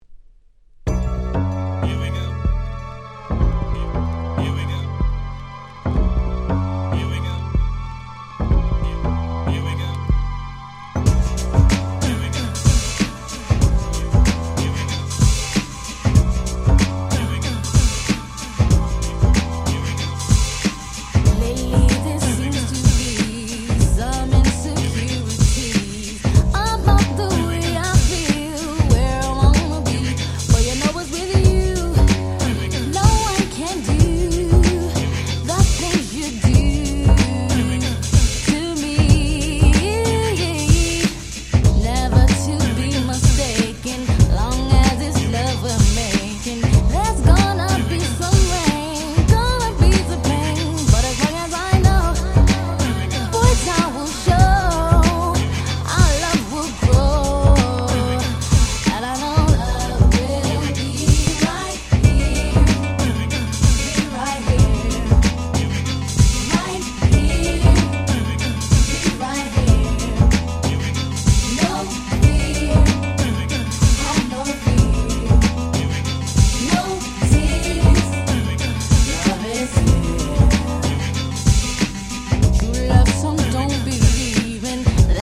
93' Super Hit R&B !!